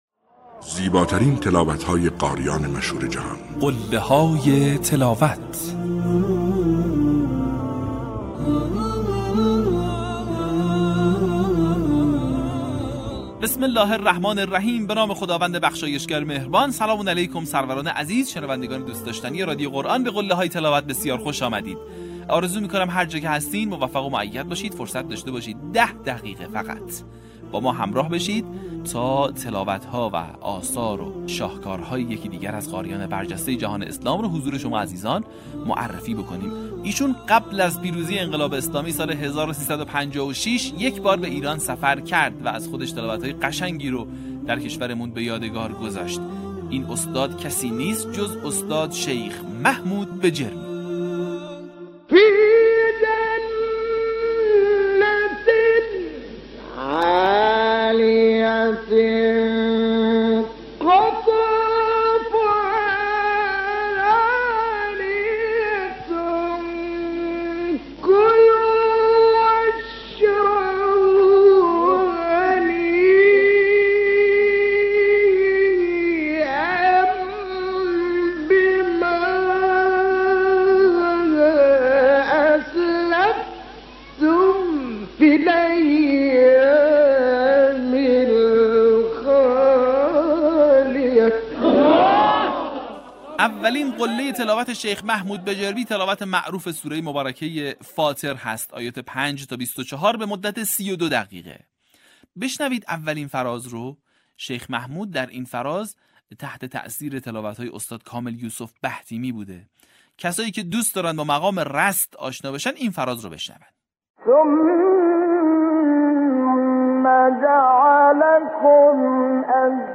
در قسمت پنجاه فراز‌های شنیدنی از تلاوت‌های به‌یاد ماندنی استاد «محمود بجیرمی» را می‌شنوید.
برچسب ها: قله های تلاوت ، محمود بجیرمی ، فراز تقلیدی ، تلاوت ماندگار